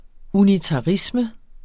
Udtale [ unitɑˈʁismə ]